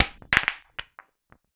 Ball Impact Break.wav